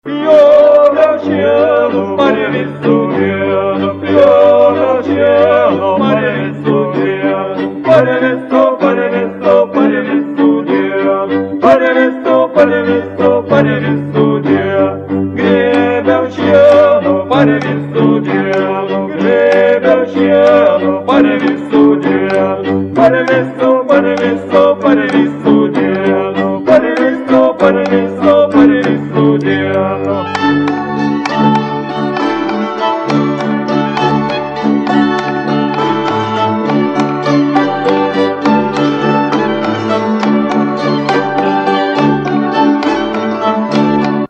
Paired dances